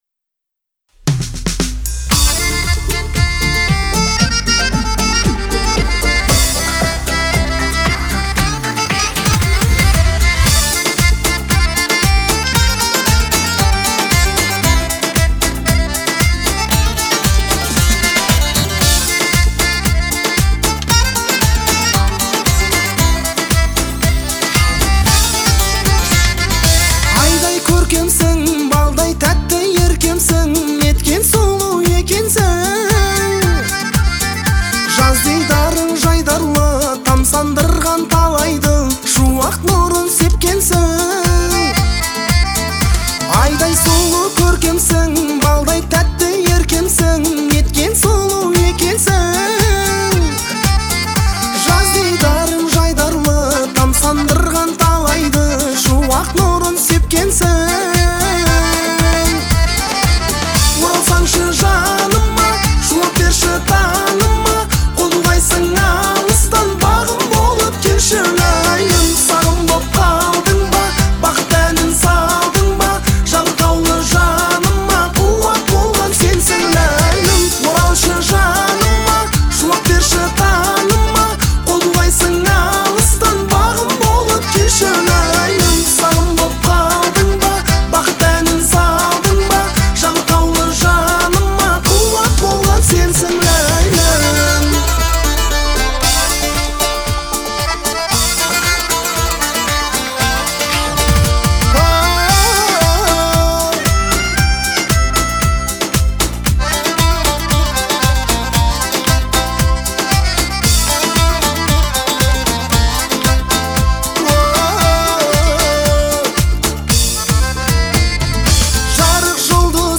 это яркий представитель казахской поп-музыки